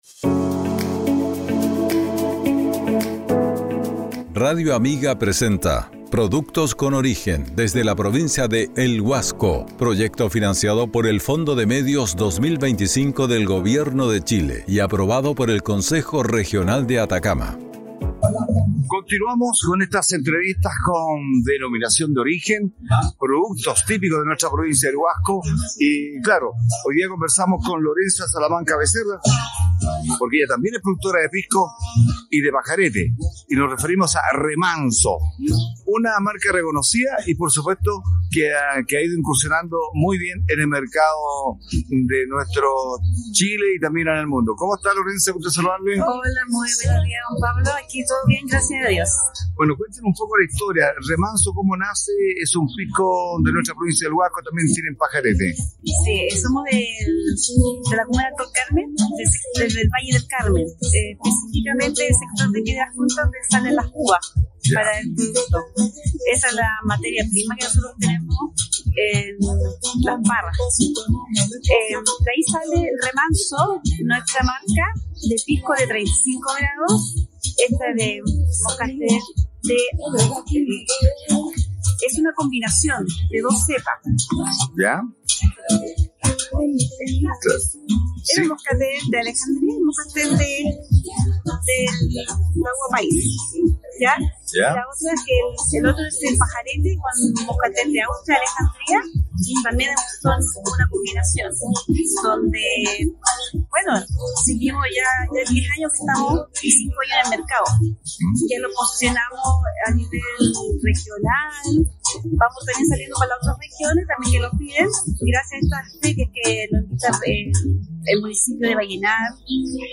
entrevistó